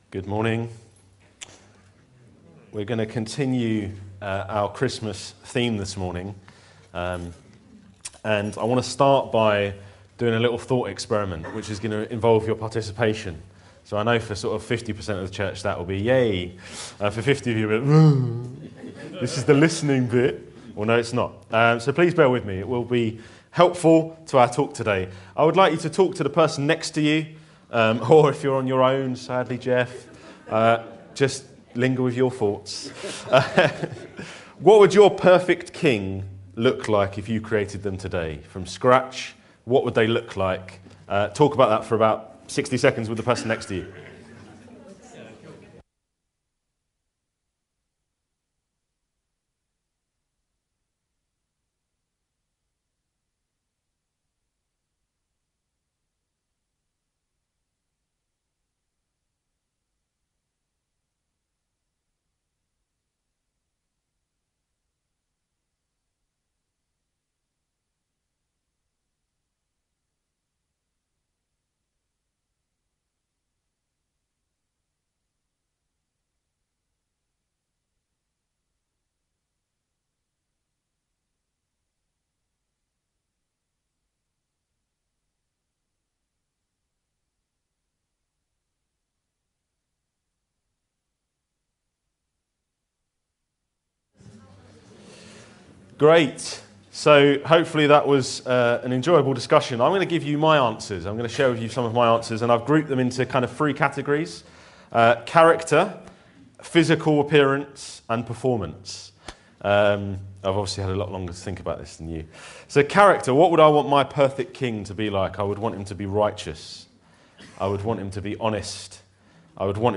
This sermon proclaims the unrivalled Kingship of Jesus Christ through comparison with King Herod.